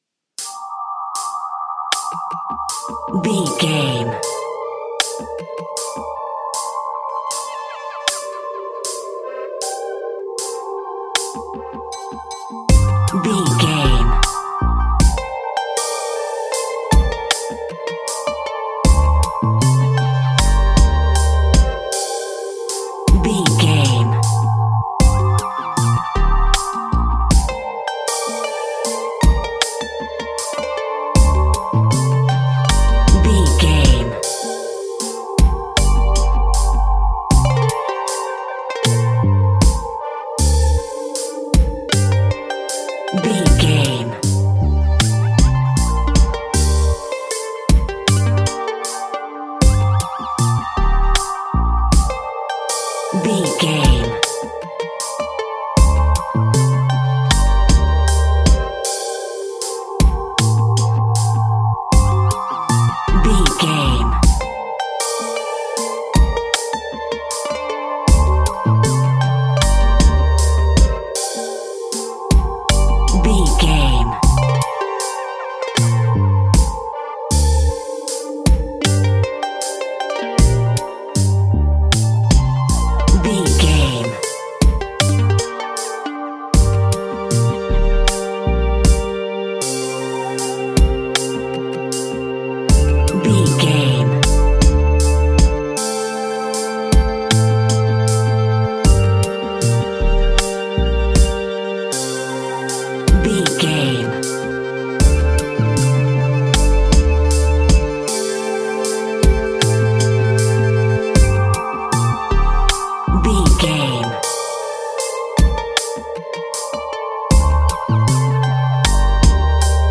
Aeolian/Minor
Slow
dreamy
meditative
melancholy
hypnotic